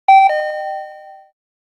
pinpon.ogg